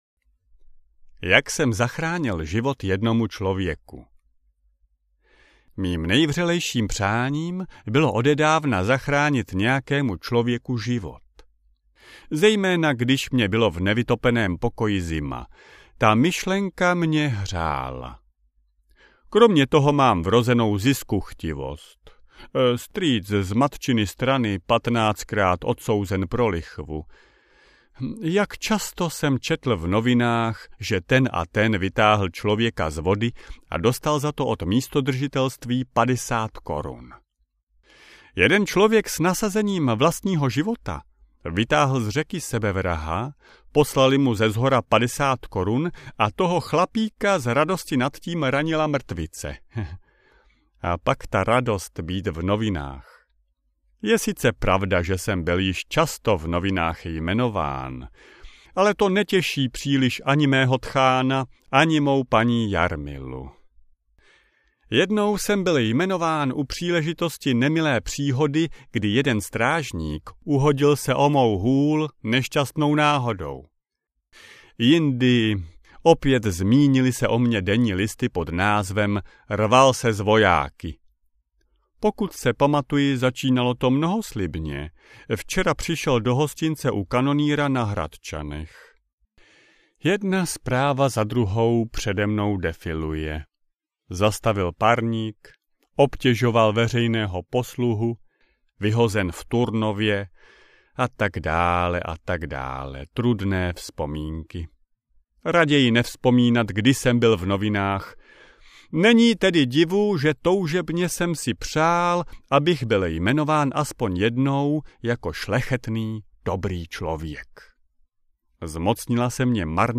Na opuštěné latríně audiokniha
Ukázka z knihy